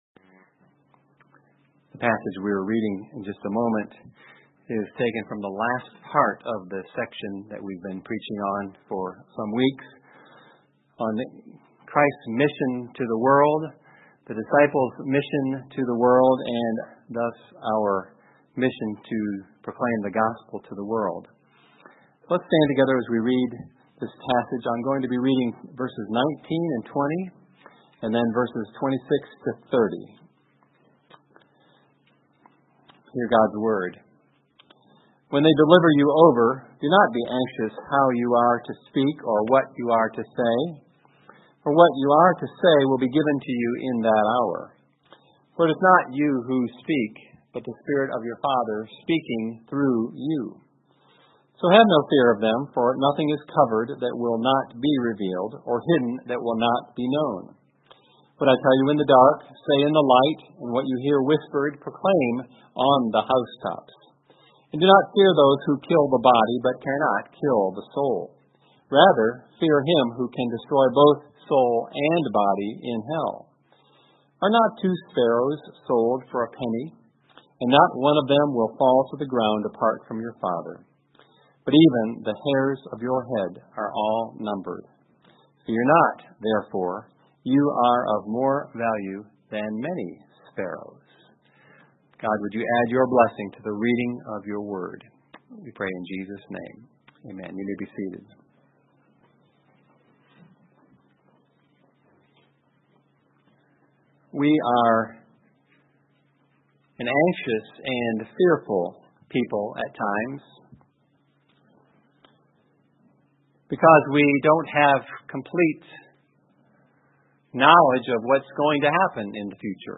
Sermon Text: Matthew 10:19-20, 26-32